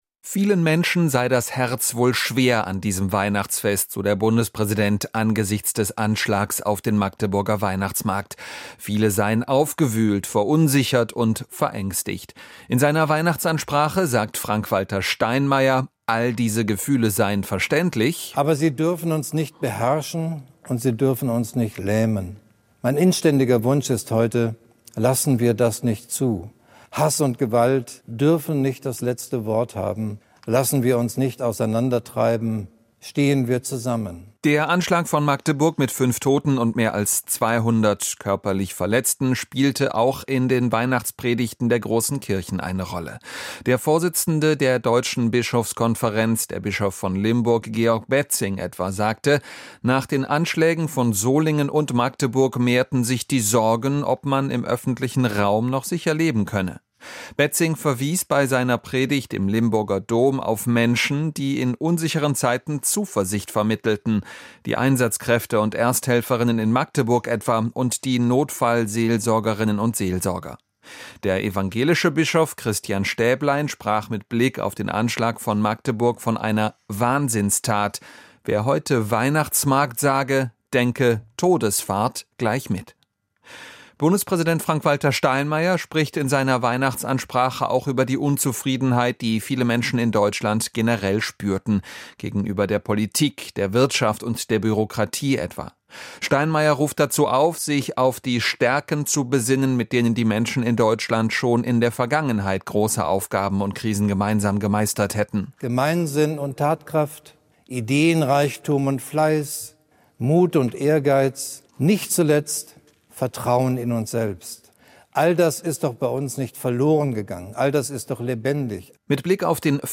Weihnachtsansprache - Bundespräsident Steinmeier zu Magdeburg und Ampel-Aus